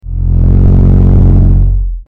Electronic Pulse 03
electronic_pulse_03.mp3